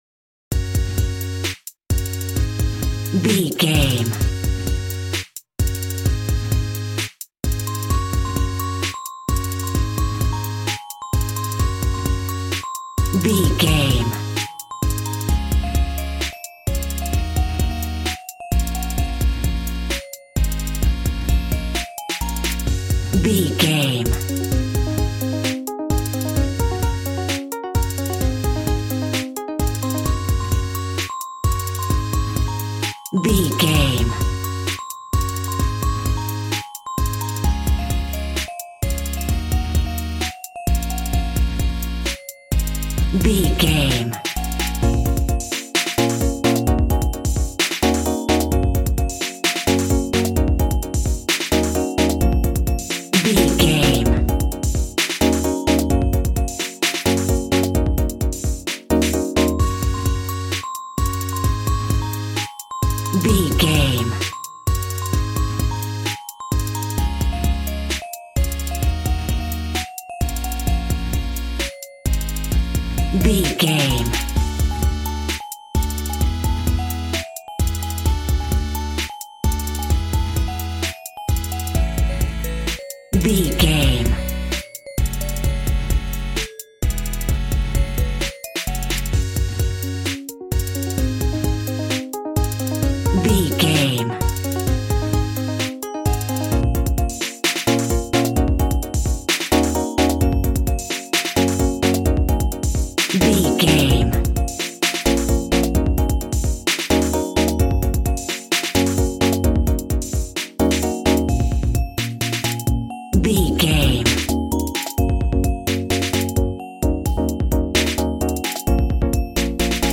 Ionian/Major
D♭
calm
smooth
synthesiser
piano